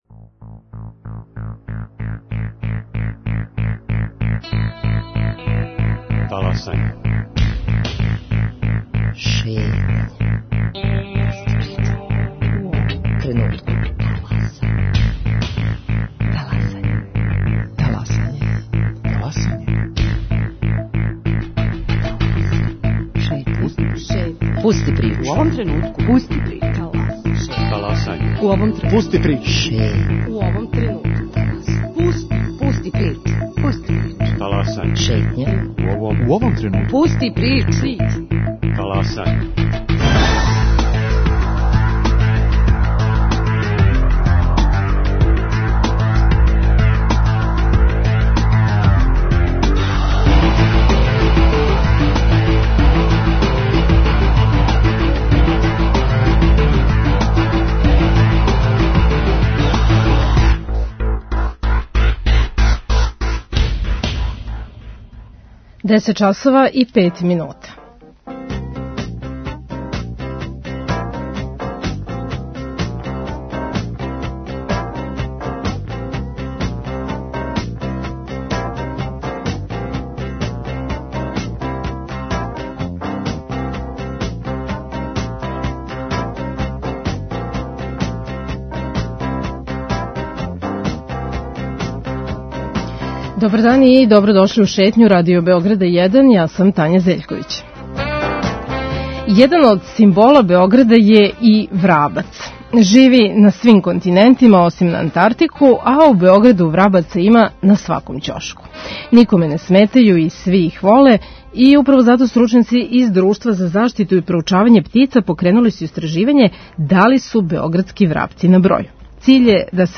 Таласање - Шетња | Радио Београд 1 | РТС